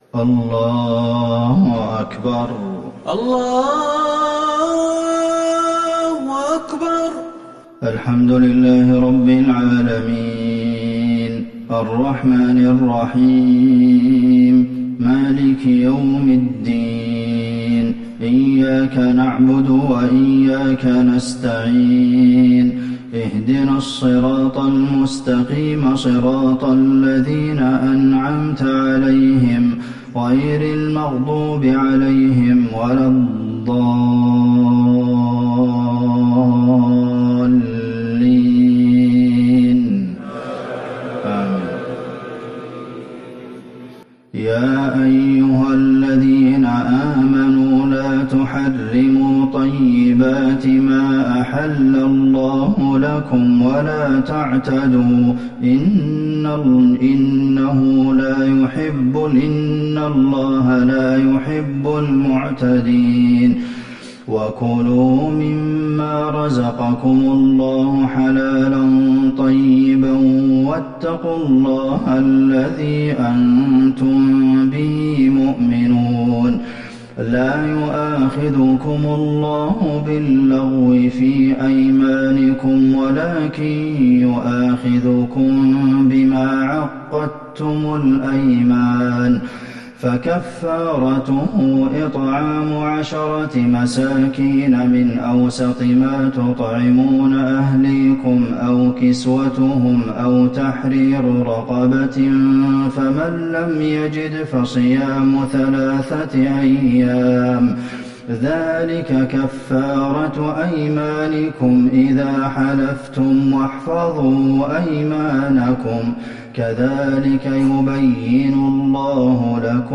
صلاة العشاء للشيخ عبدالمحسن القاسم 6 جمادي الآخر 1441 هـ
تِلَاوَات الْحَرَمَيْن .